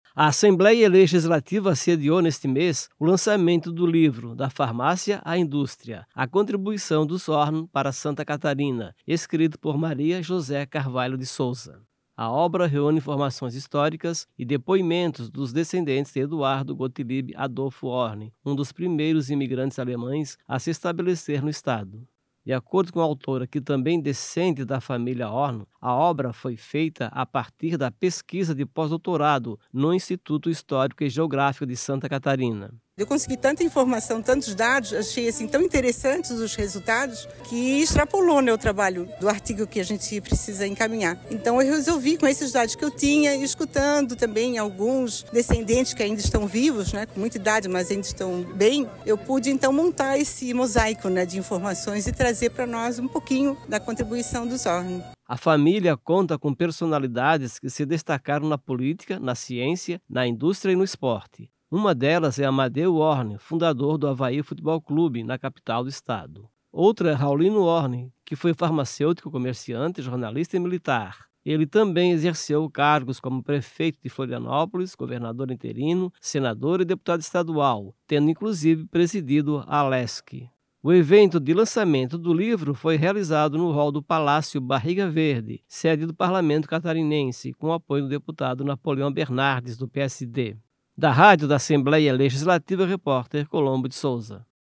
Entravista com: